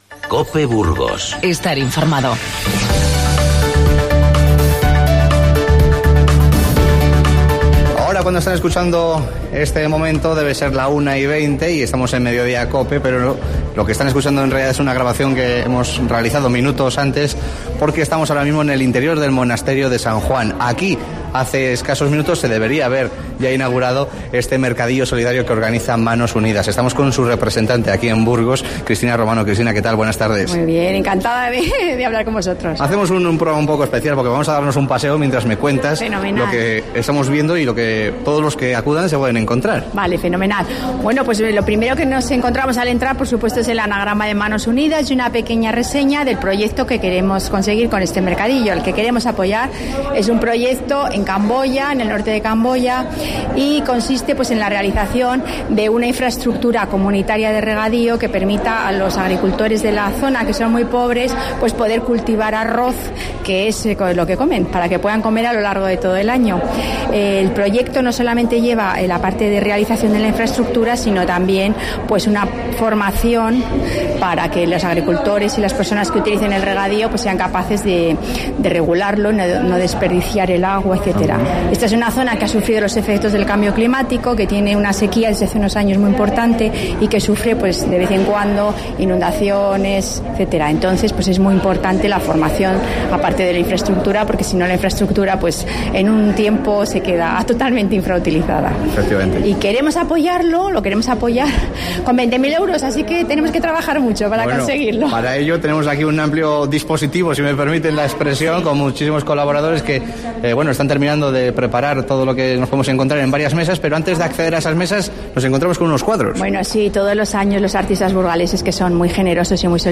Damos un paseo sonoro por el Monasterio de San Juan, donde Manos Unidas organiza un mercadillo solidario para conseguir fondos de cara a un proyecto de cooperación con Camboya.